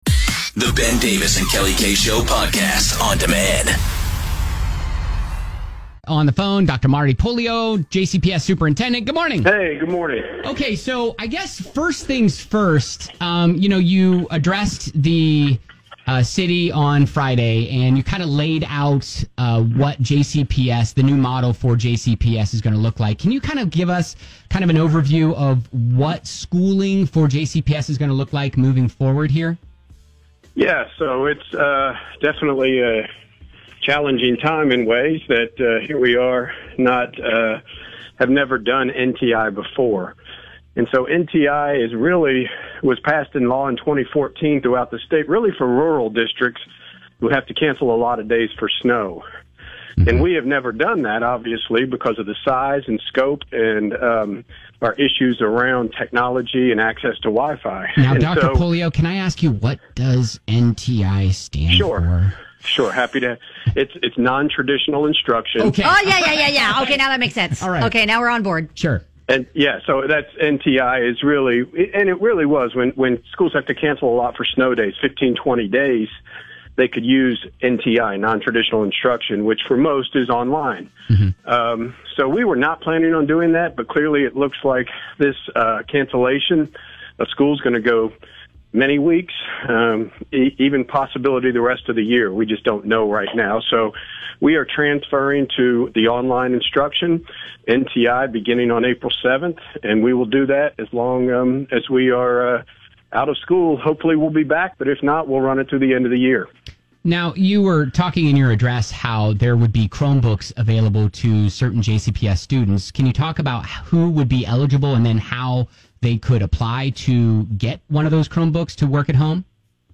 There are a lot of questions regarding schooling and education amidst the COVID-19 pandemic. JCPS Superintendent Dr. Marty Pollio talks to the show about the next steps.